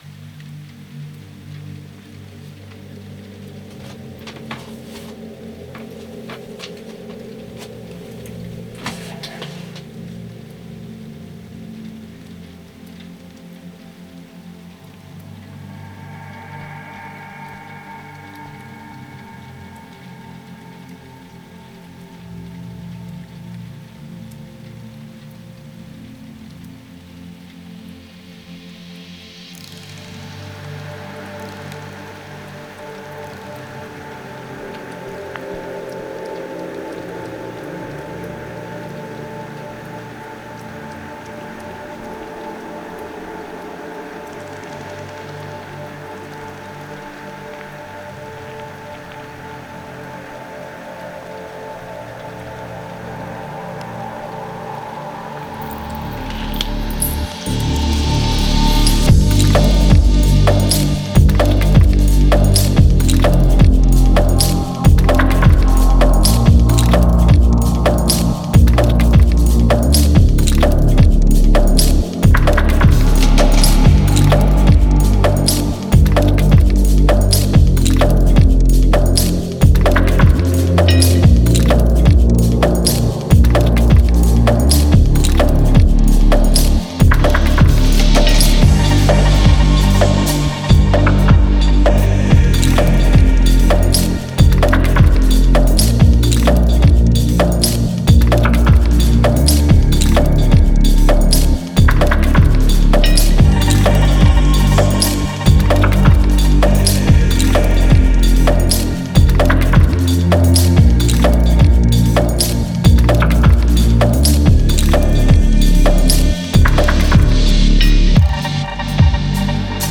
это атмосферная композиция в жанре альтернативного рока